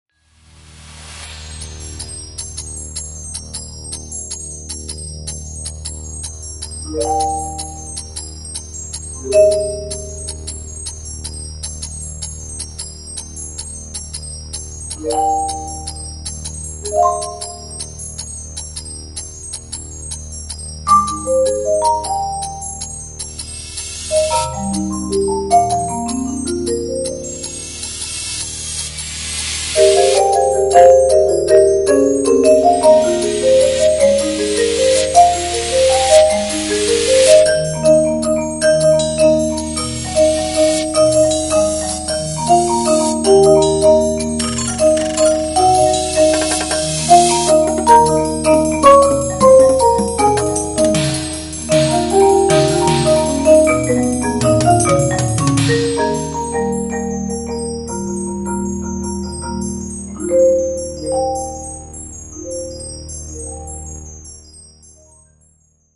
for Marimba and Soundscape
[Four mallets - 4 1/3 octave marimba].